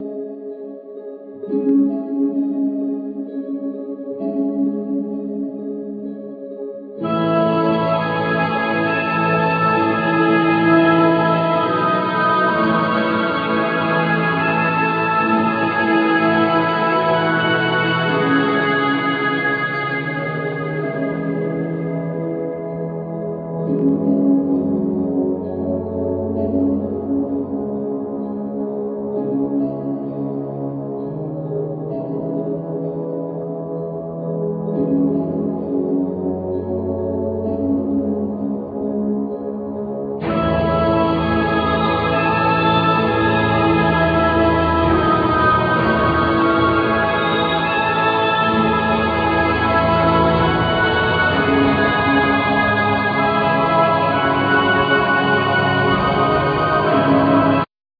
Backing vocals,Dulcimer,Drums,Programming